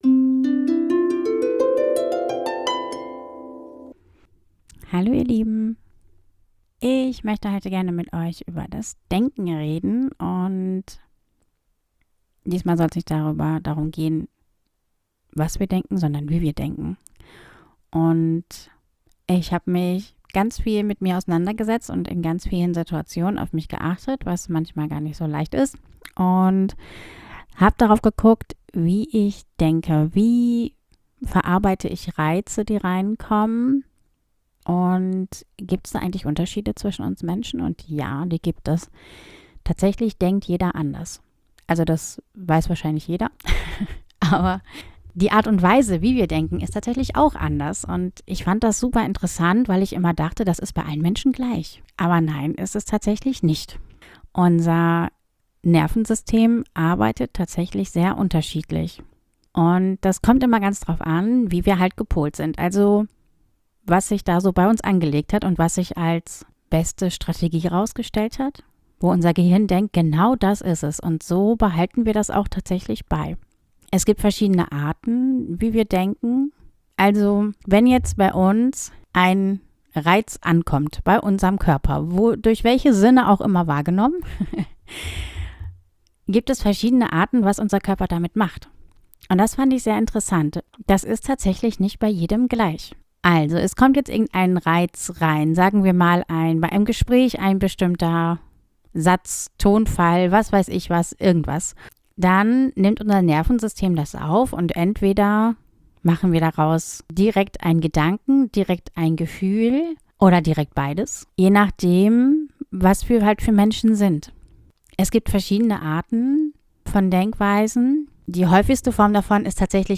In dieser Folge geht es darum, wie Denkweisen entstehen, was zwischen Reiz und Reaktion passiert und warum unser Inneres wie ein Mischpult funktioniert mit Reglern für Kopf, Gefühl und Körper. Eine ruhige, verständliche Einladung, die eigene Art zu denken besser zu verstehen.